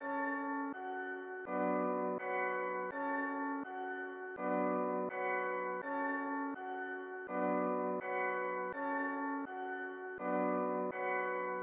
标签： 165 bpm Trap Loops Piano Loops 1.96 MB wav Key : E
声道立体声